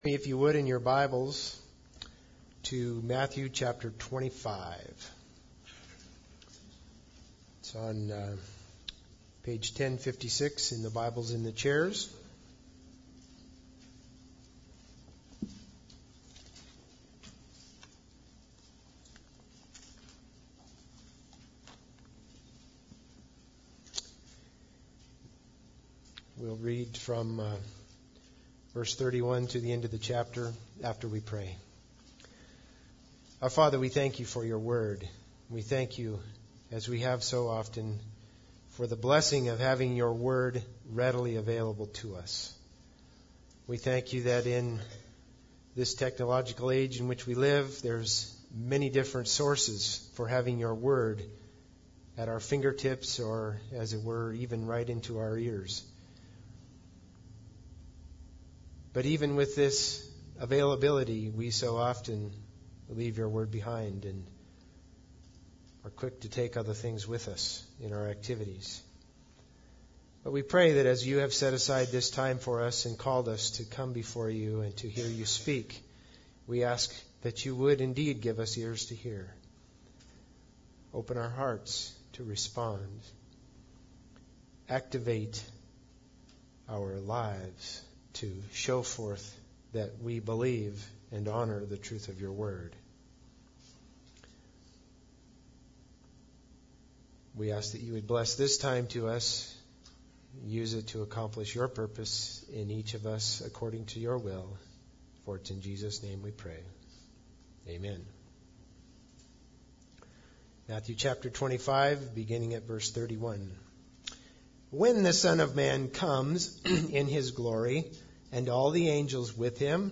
Passage: Matthew 25:31-46 Service Type: Sunday Service